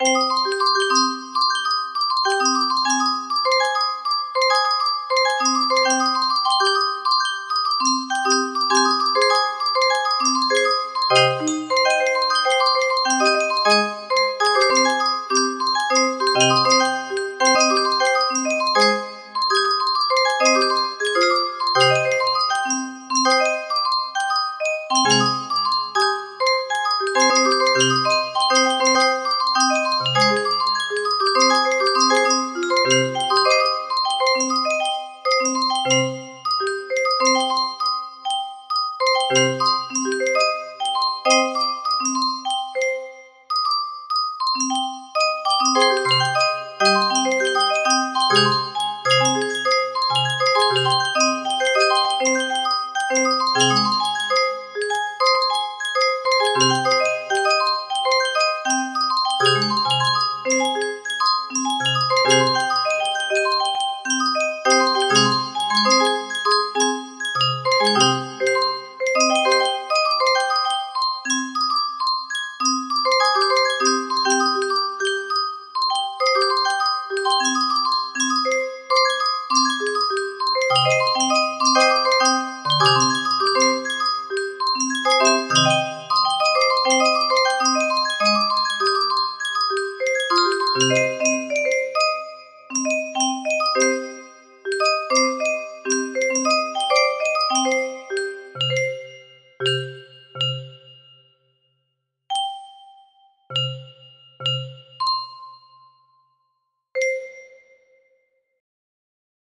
Threads Of Gold 7 music box melody
Full range 60